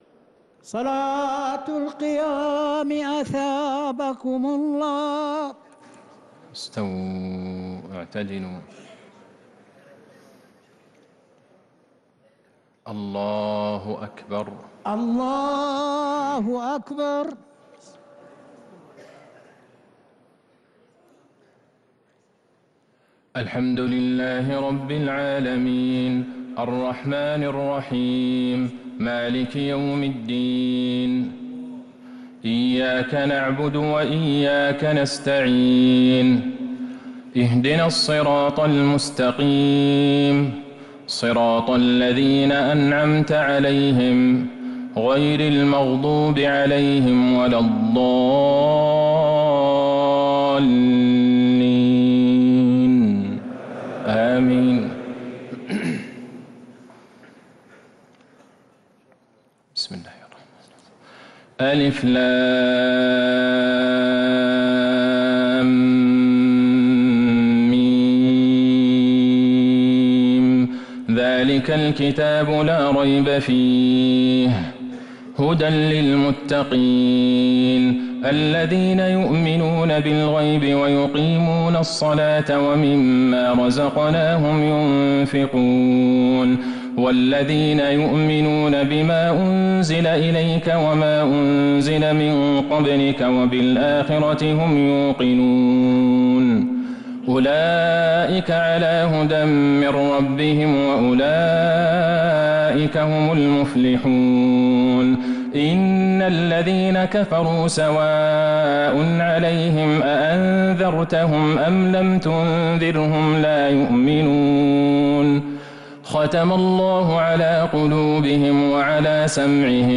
تراويح ليلة 1 رمضان 1447هـ من سورة البقرة (1-66) | Taraweeh 1st night Ramadan 1447H > تراويح الحرم النبوي عام 1447 🕌 > التراويح - تلاوات الحرمين